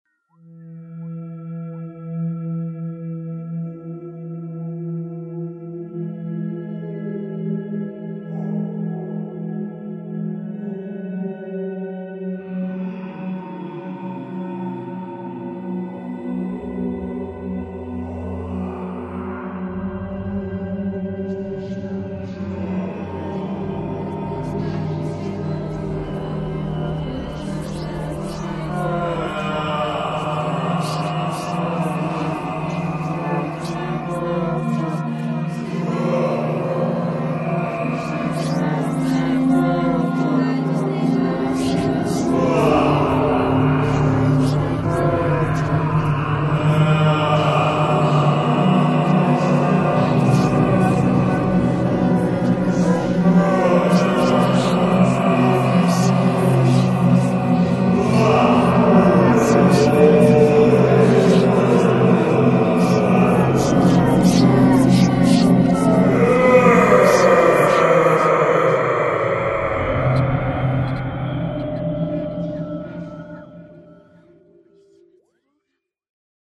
Звук умирания, когда душа покидает тело и приходят духи